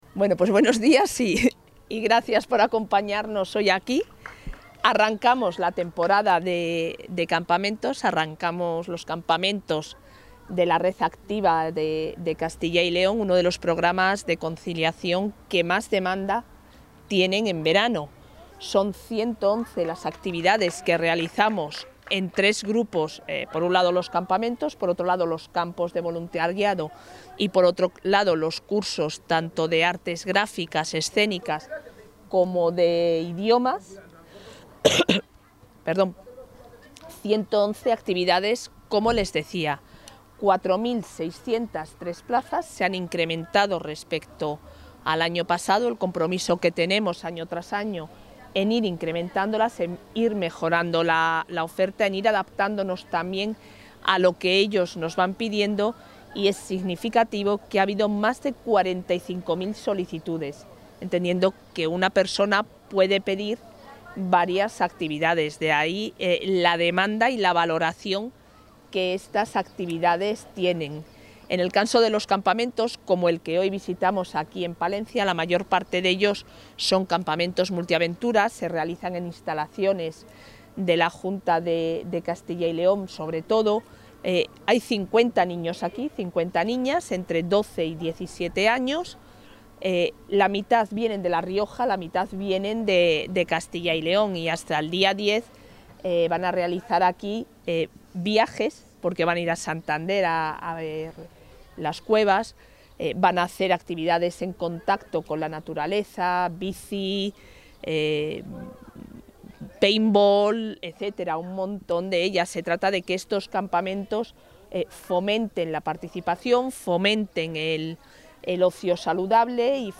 Intervención del portavoz.